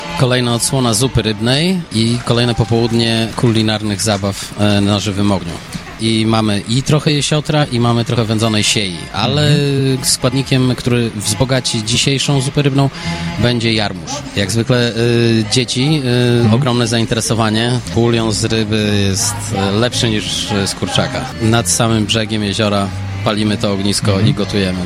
Na miejscu są też nasi reporterzy, którzy na żywo zdają relacje z wydarzenia.